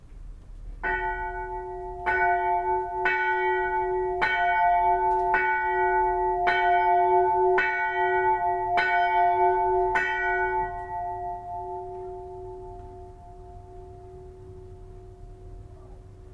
Petite cloche à la pince mince.
(1) La petite cloche IV, que je mets en volée manuellement.